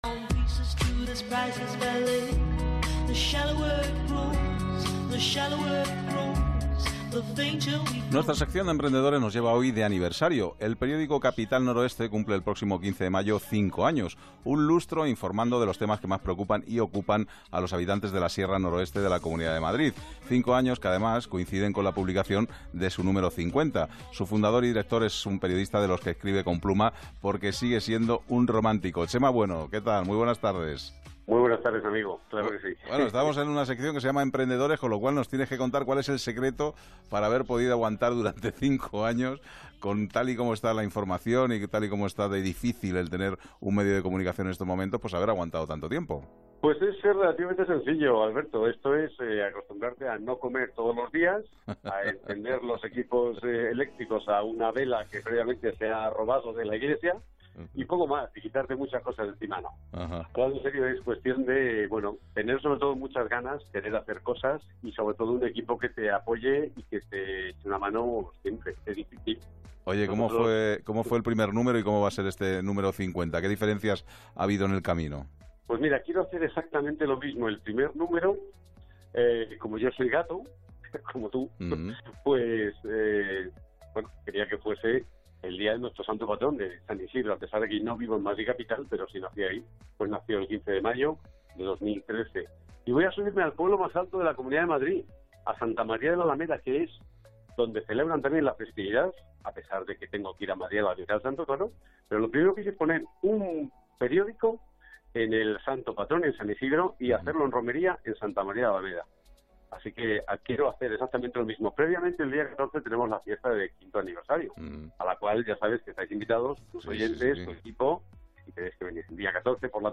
Entrevista-5-Aniversario-en-Onda-Cero-MP3.mp3